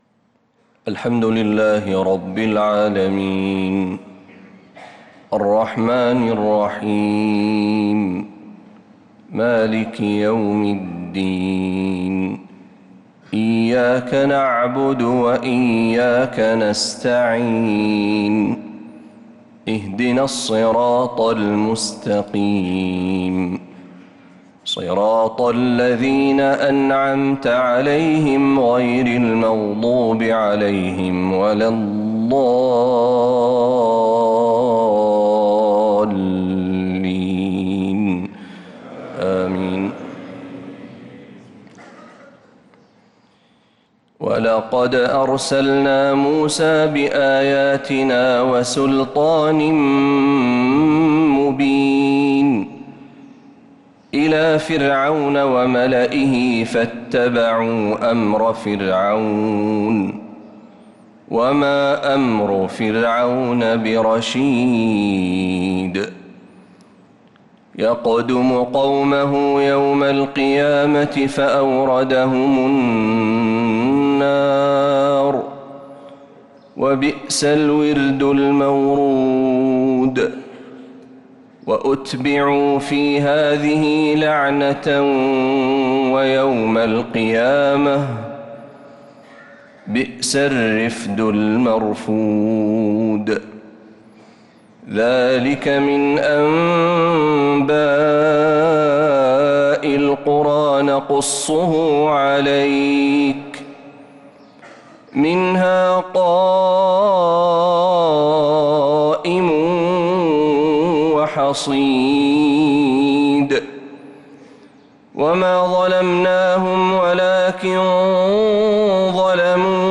فجر الأربعاء 8-7-1446هـ من سورة هود 96-116 | Fajr prayer from Surat Hud 8-1-2025 > 1446 🕌 > الفروض - تلاوات الحرمين